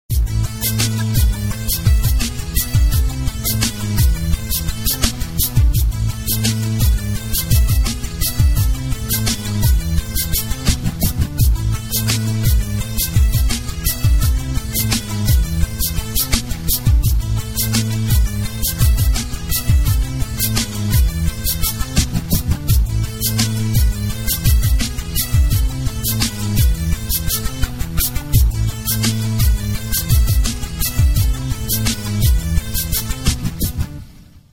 Rap Remix